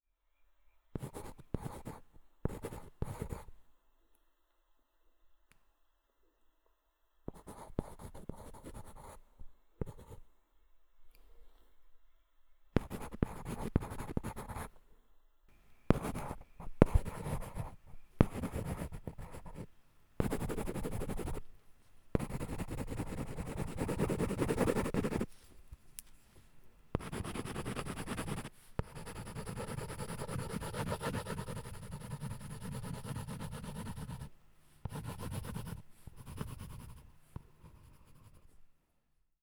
pencil-scratch-2.wav